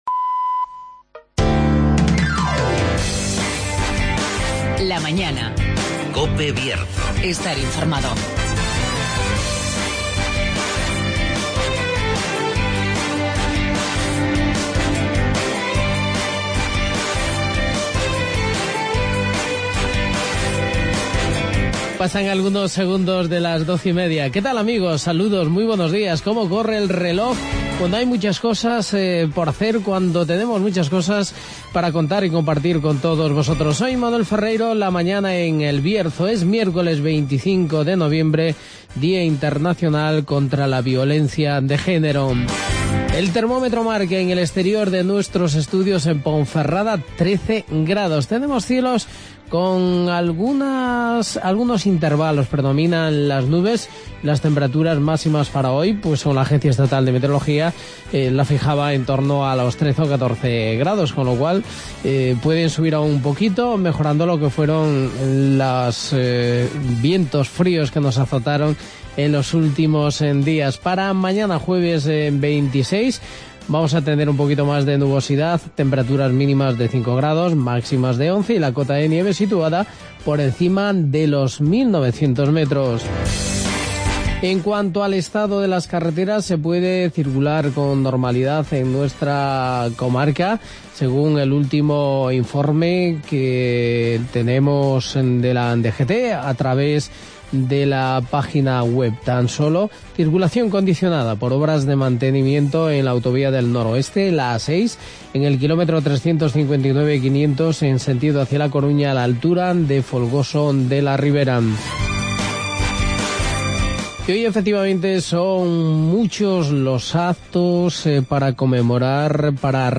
AUDIO: Presentación, avance informativo